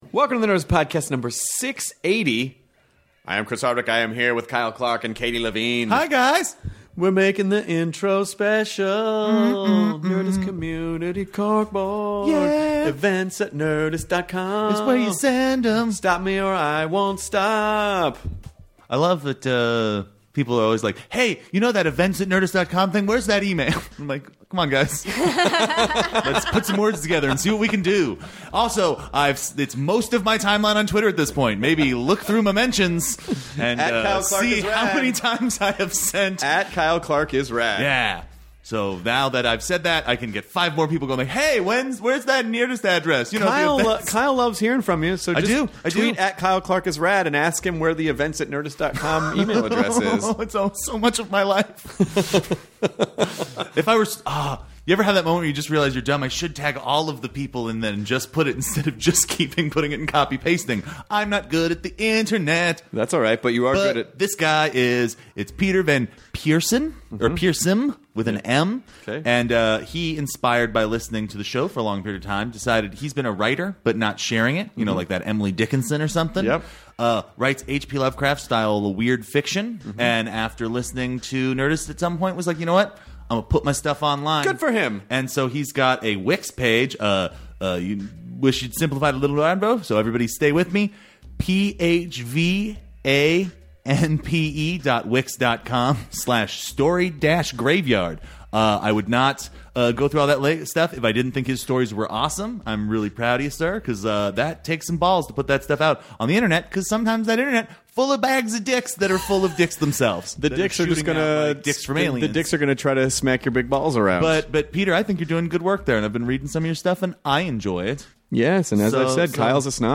Richard Lewis (comedian, Curb Your Enthusiasm, Leaving Las Vegas) talks with Chris about the changes in his comedy over the years, how he think his upbringing has inspired his jokes and coming up with jokes in his dreams. They also talk about the big comedy boom in the 80’s, how people watched Richard growing up and his new book Reflections From Hell!